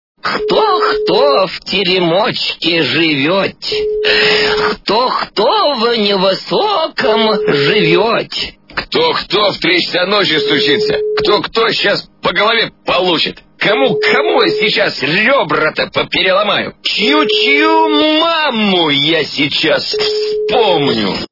» Звуки » Смешные » Кто-то в теремочке живет? - Кто-то в три часа ночи стучится?
При прослушивании Кто-то в теремочке живет? - Кто-то в три часа ночи стучится? качество понижено и присутствуют гудки.